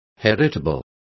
Complete with pronunciation of the translation of heritable.